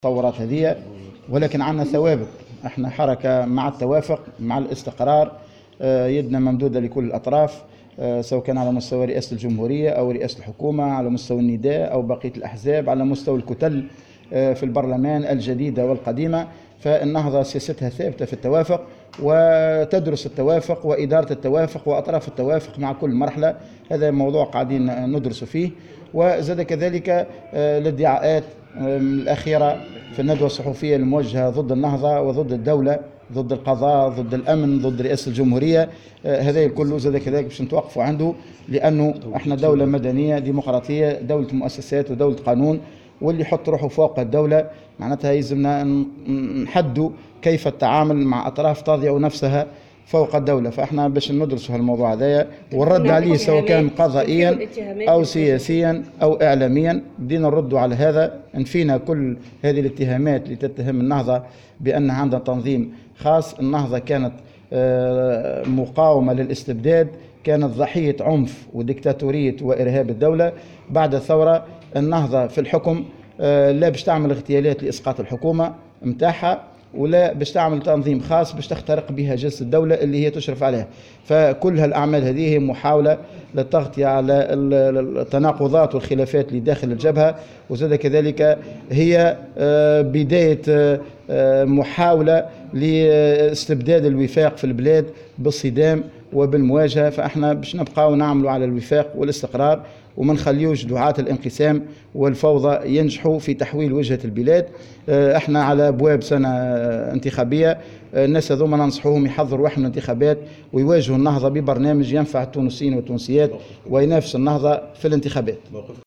وقال في تصريح لمراسلة "الجوهرة اف أم"، على هامش انطلاق أعمال الدورة 22 للمجلس شورى النهضة، على مدى يومين بالحمامات، إن النهضة سترد على هذه الاتهامات قضائيا أو اعلاميا أو سياسيا.